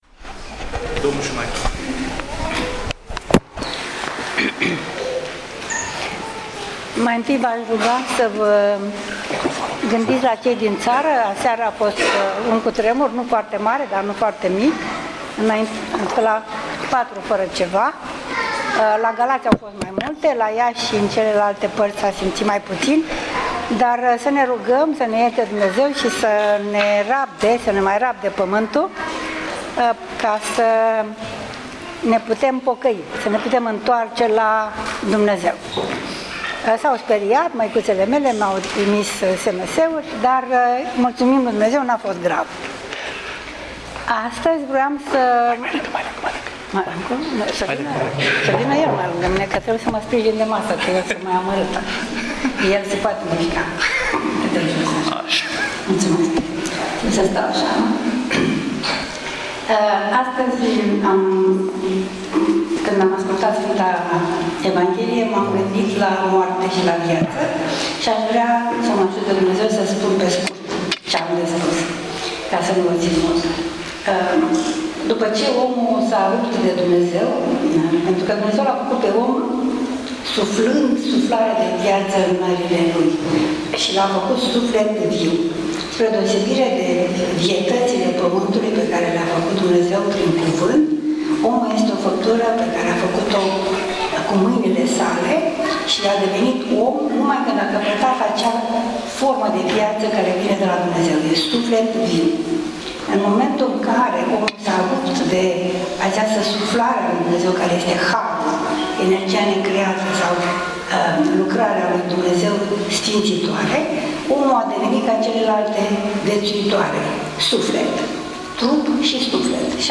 Cuvânt după Sfânta Liturghie, Sttutgart, octombrie 2013 | Centrul de formare şi consiliere Sfinţii Arhangheli Mihail şi Gavriil